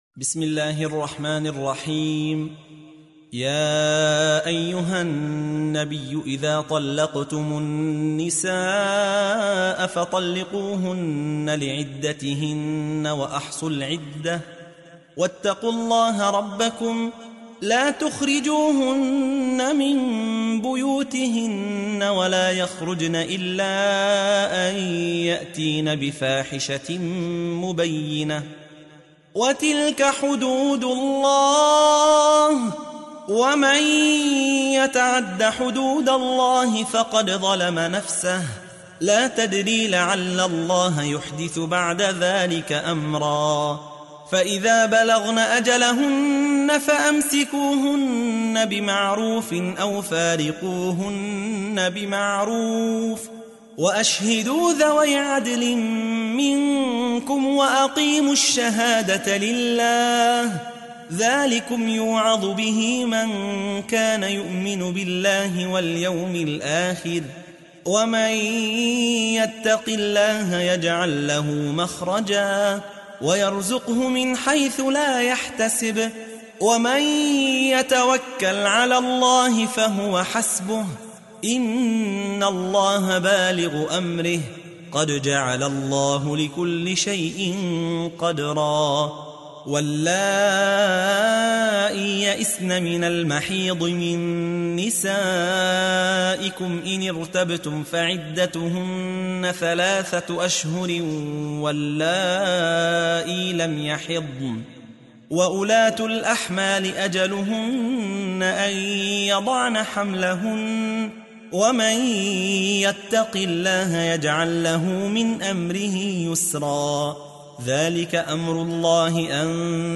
65. سورة الطلاق / القارئ